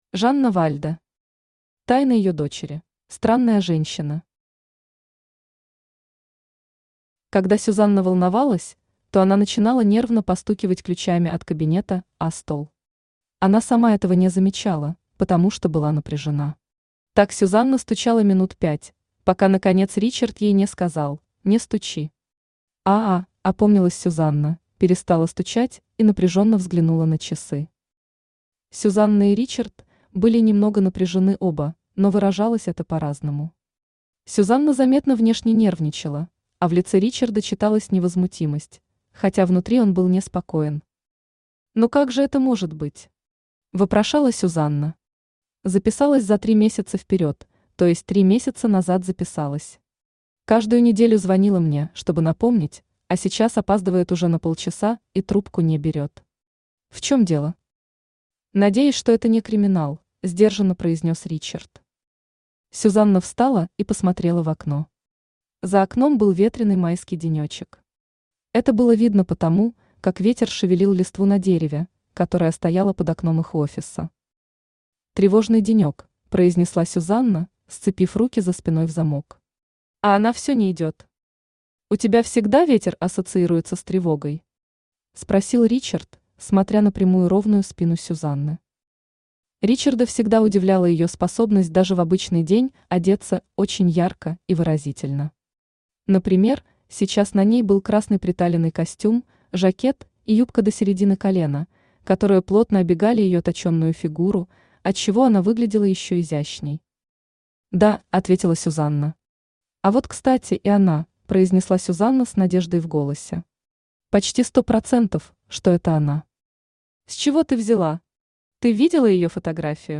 Aудиокнига Тайна ее дочери Автор Жанна Вальда Читает аудиокнигу Авточтец ЛитРес.